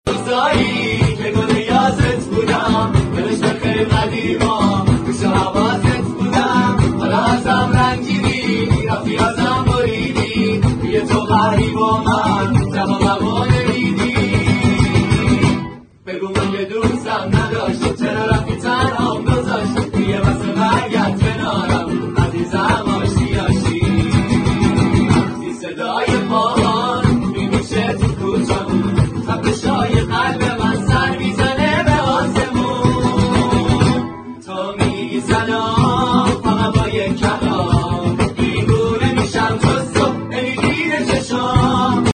اجرای گروهی با گیتار